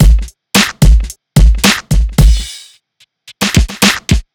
Ударные и барабаны (RnB Soul): Human C